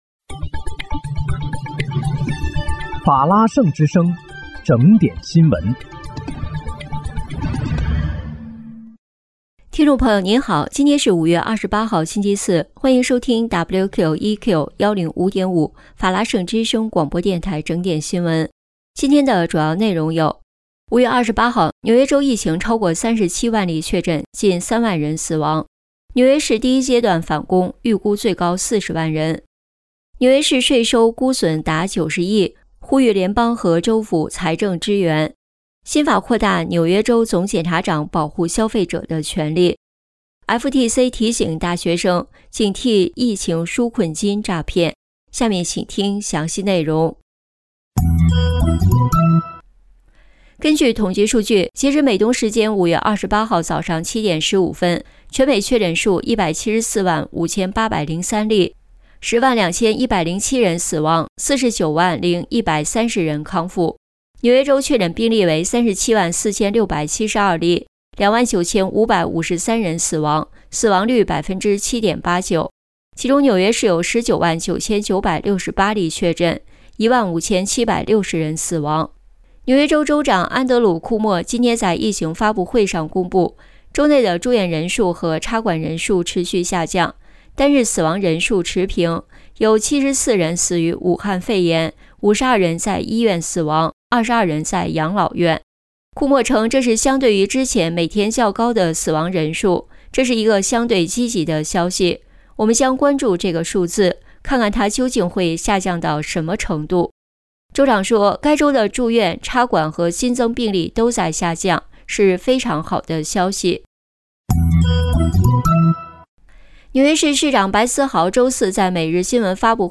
5月28日（星期四）纽约整点新闻